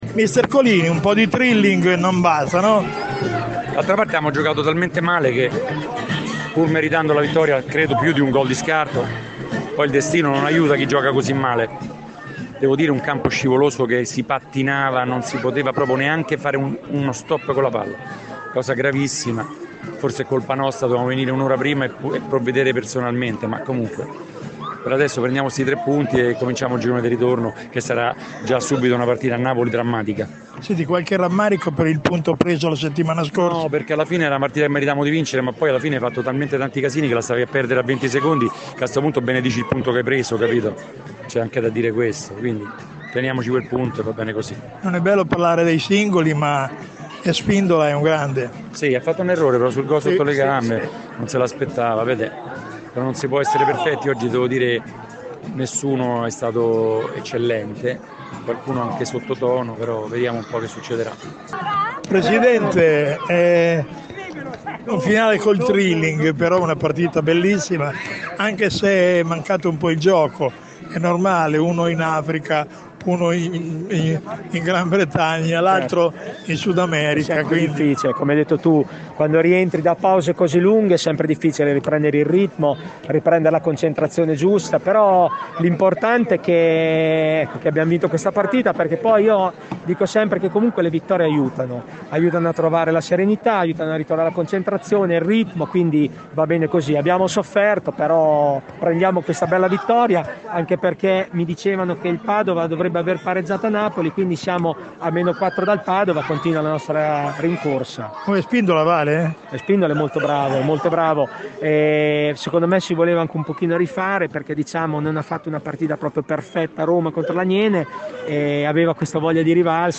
L’Italservice Pesaro torna di fronte ai suoi tifosi dopo due mesi e subito conferma le sue buone abitudini di casa. Le interviste post gara ai protagonisti del match